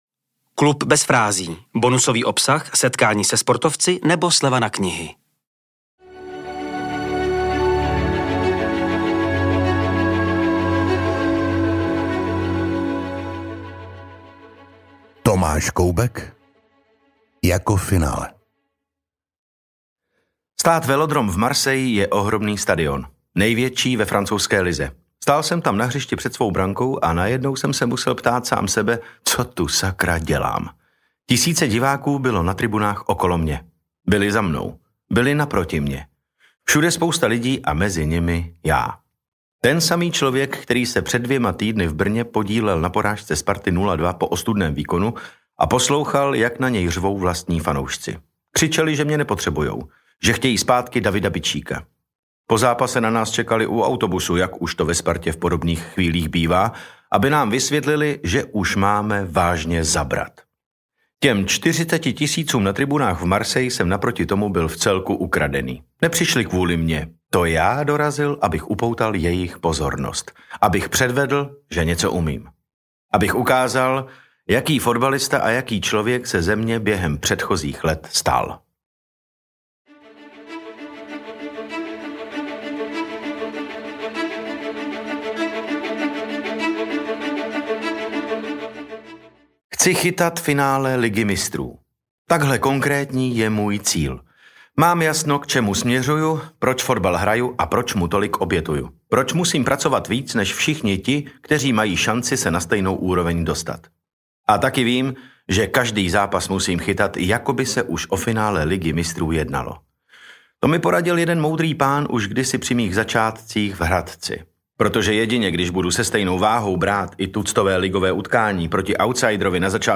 Upřímné vyprávění pro vás načetl herec a fotbalový fanoušek David Novotný .